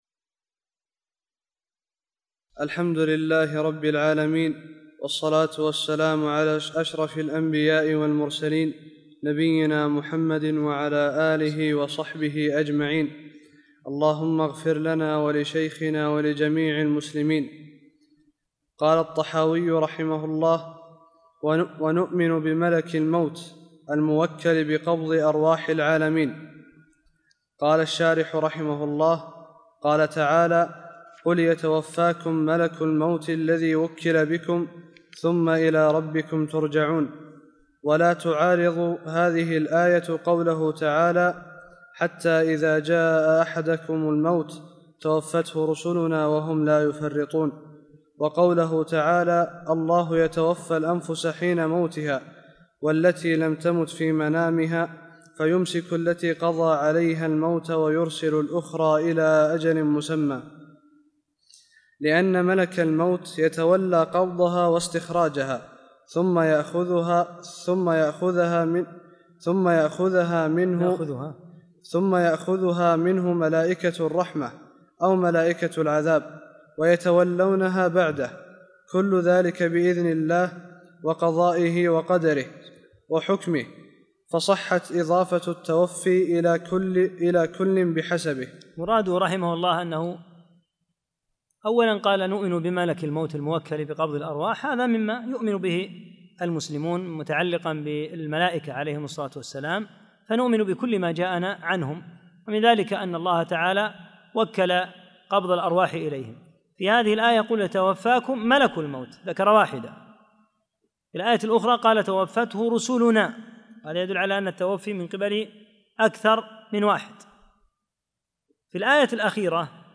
19 - الدرس التاسع عشر